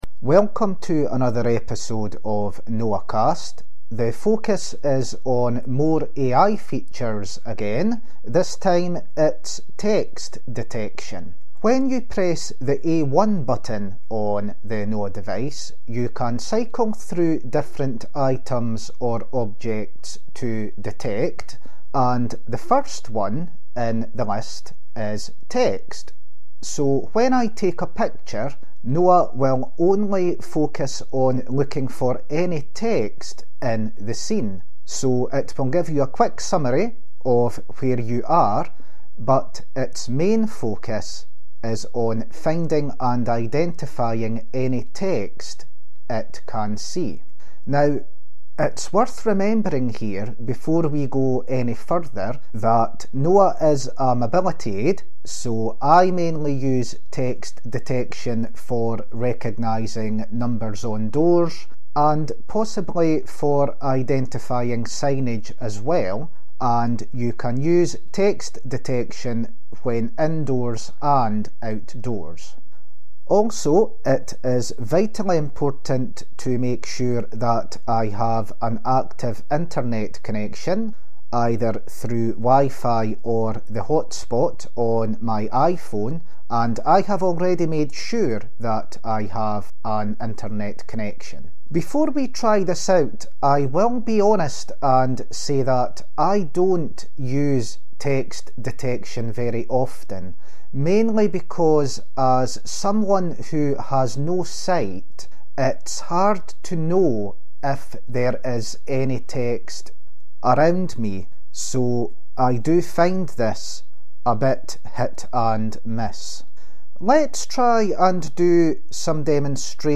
Demonstration of the text detection feature both indoors and outdoors which I mainly use for reading address numbers on doors.